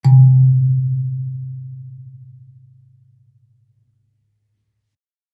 kalimba_bass-C2-ff.wav